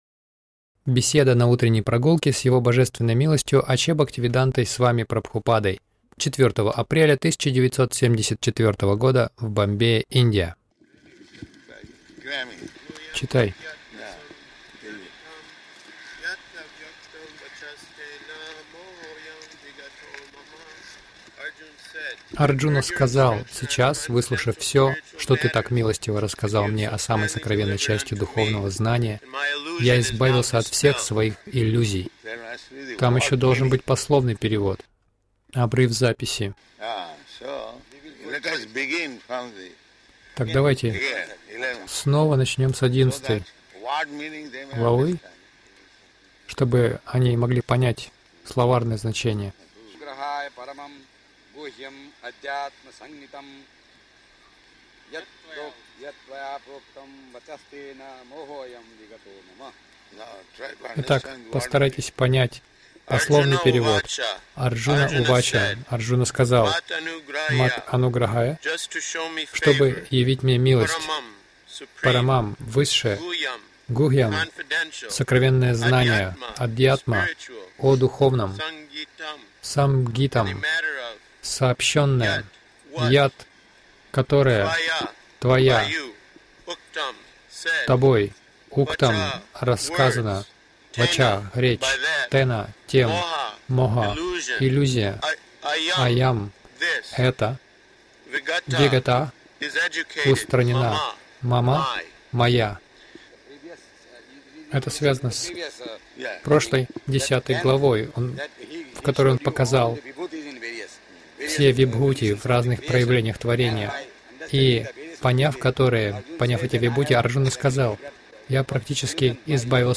Утренние прогулки — Чтение и обсуждение 11 гл. Бхагавад Гиты
Милость Прабхупады Аудиолекции и книги 04.04.1974 Утренние Прогулки | Бомбей Утренние прогулки — Чтение и обсуждение 11 гл.